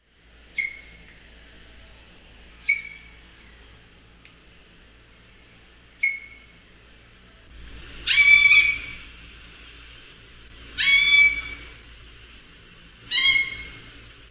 Grey Parrot
Psittacus erithacus